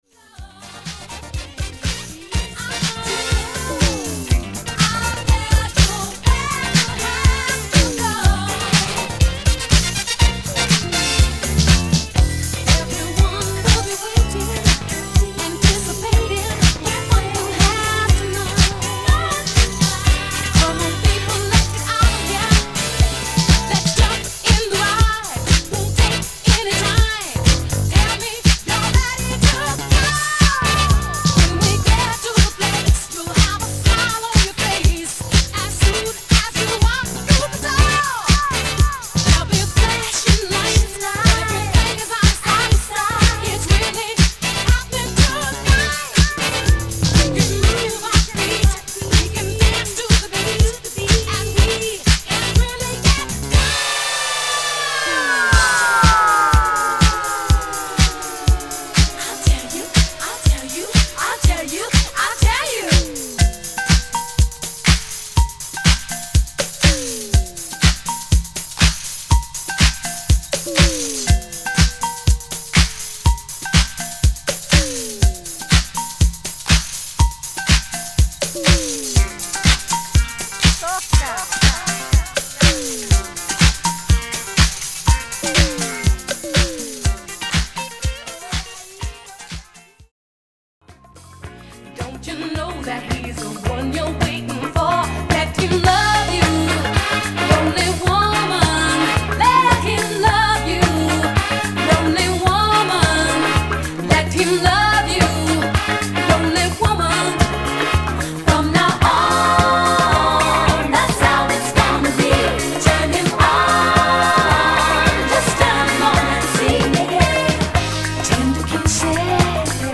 PARTY GROOVEまき散らす傑作